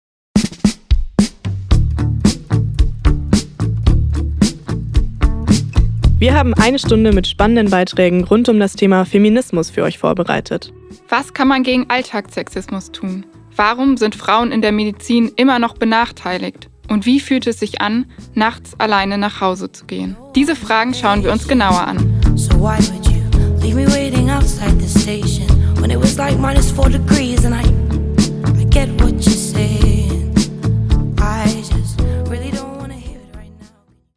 Dazu gibt’s spannende Talks mit einer Expertin für feministische Außenpolitik und einer kreativen Künstlerin, die analoge Fotografie neu denkt.